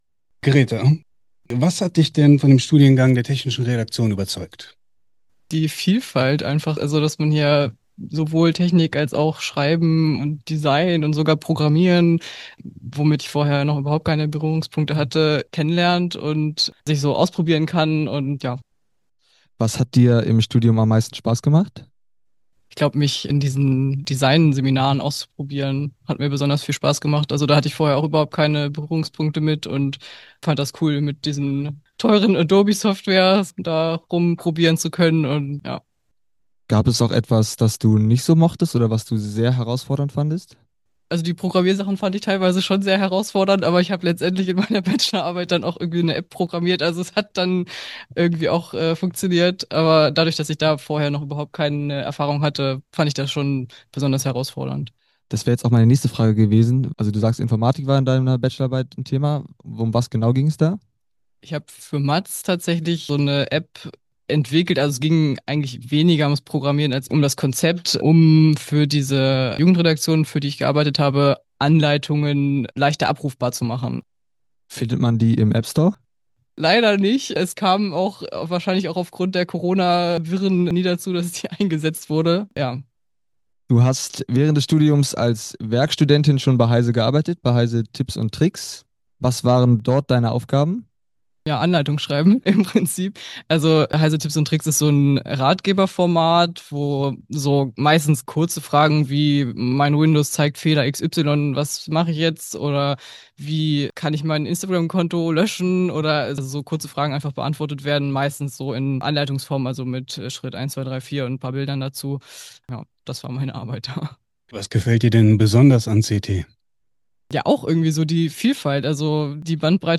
Technische Redakteur*innen im Gespräch